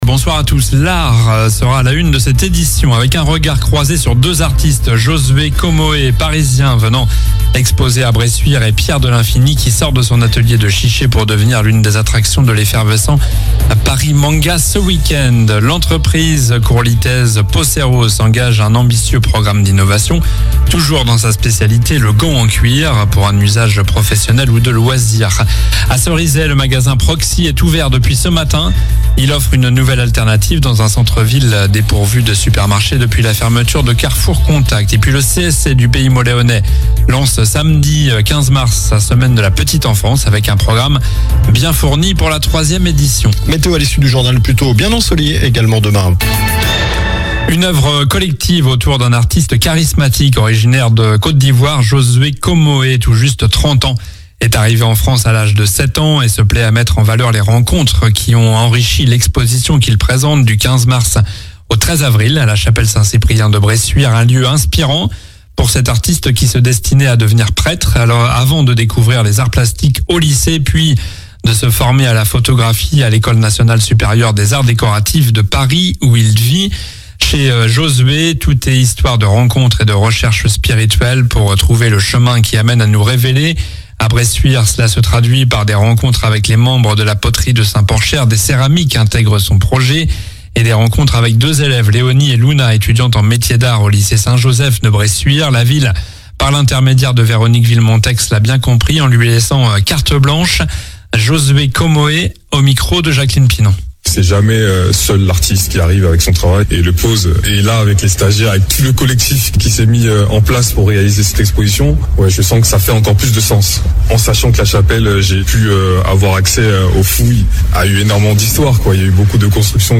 Journal du jeudi 13 mars (soir)